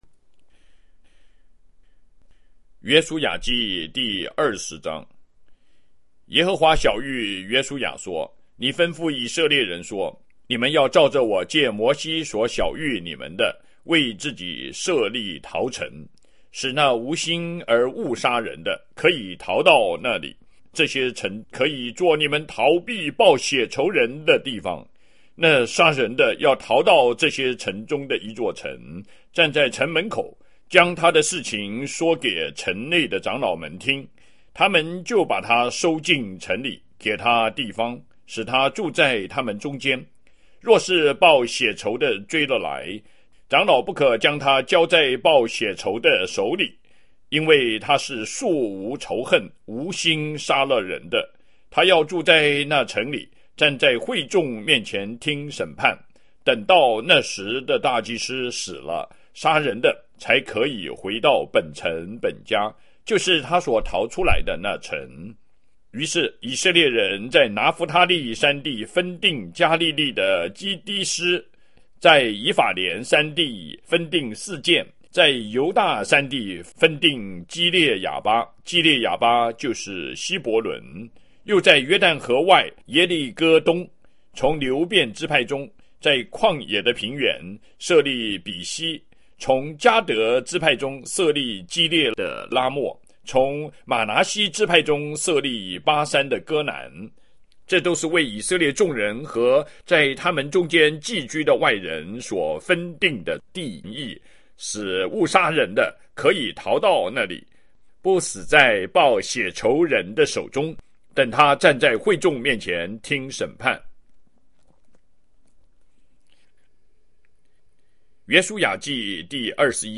經文誦讀